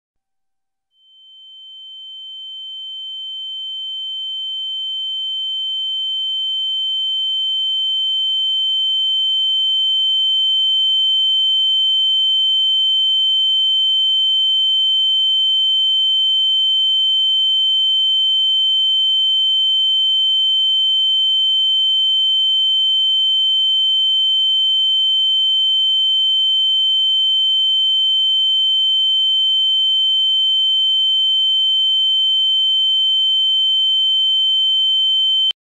Ear-Ringing-Sound-Effect.ogg